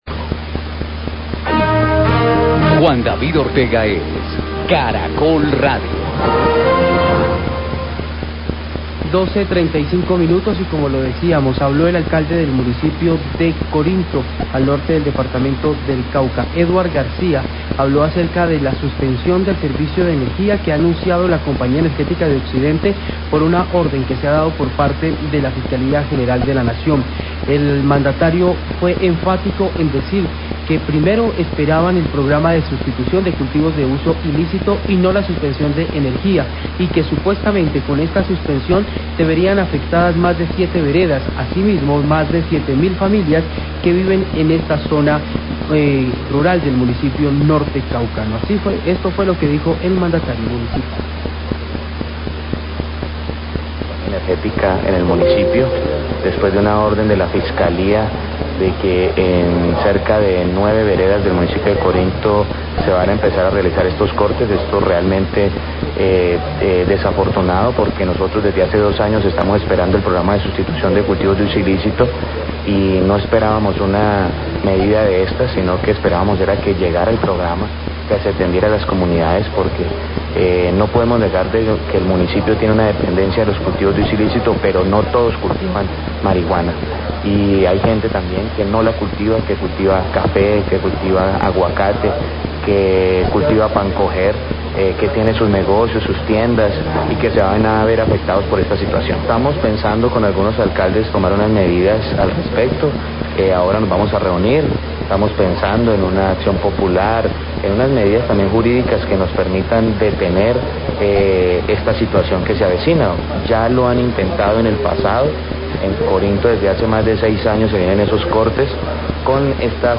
Radio
El Alcalde de Corinto, Edward Garcia habló de la orden de suspender del servicio de energía que ha anunciado la Compañía Energética por orden de la Fiscalía General de la Nación.